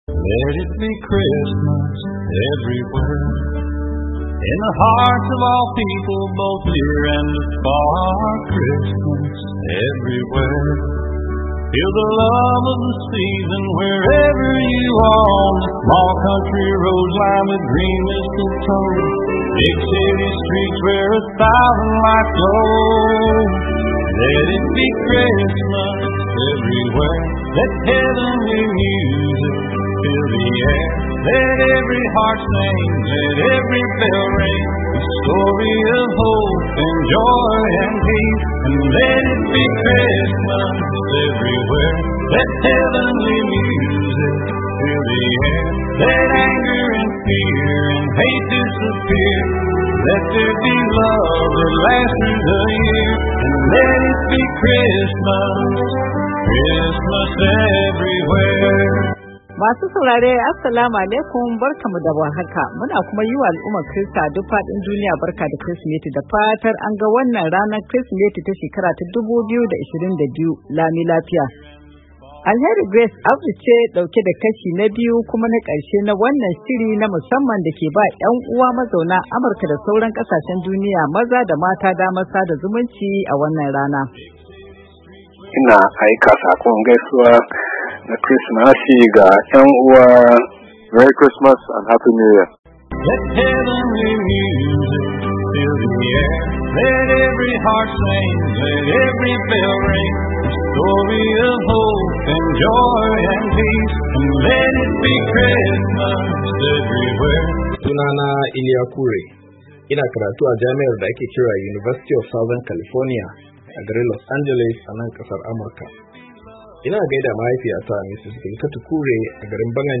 A wannan shirin mun kawo kashi na biyu na gaishe-gaishen Krismas, shiri na musamman da ke ba ‘yan’uwa mazauna Amurka maza da mata damar sada zumunci a ranar da Kiristoci a duk fadin duniya su ke bukukuwan Kirsimeti.